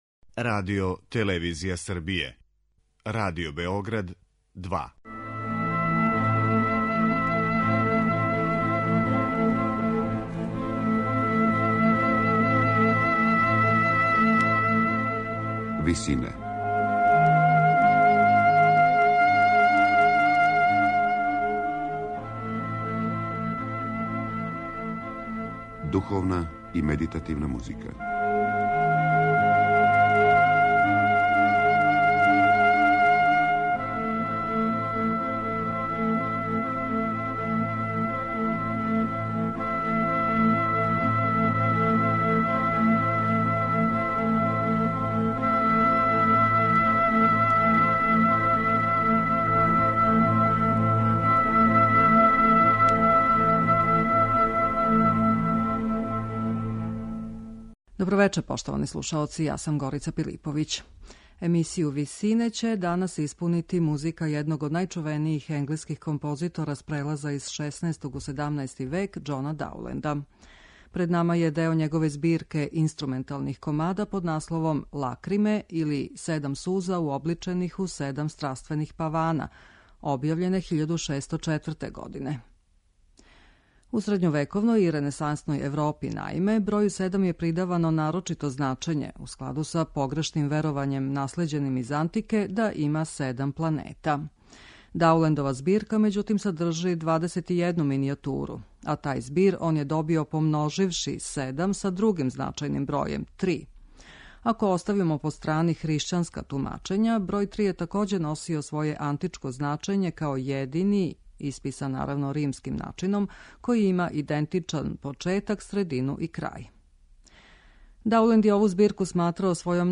Биће то његова збирка инструменталних комада под називом Лакриме или Седам суза уобличених у седам страствених павана.
Она је добар пример како његовог мајсторства, тако и доминирајућег меланхоличног осећања, карактеристичног за ту епоху уопште.